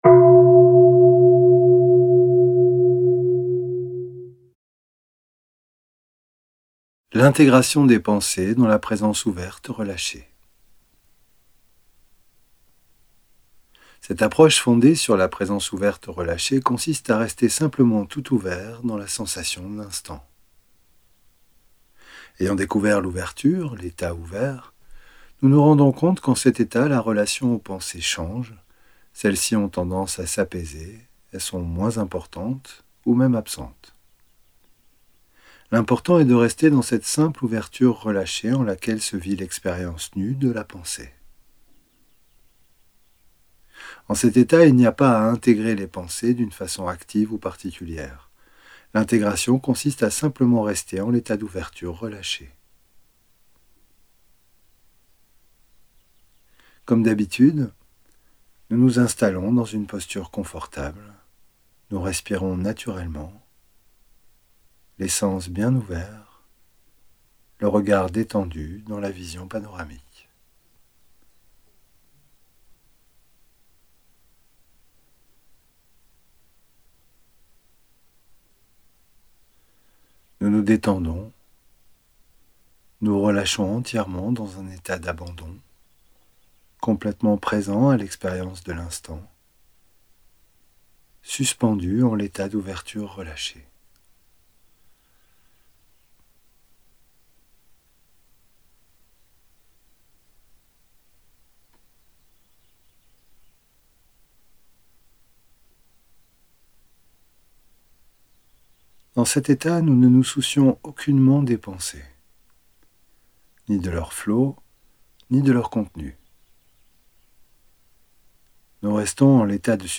Audio homme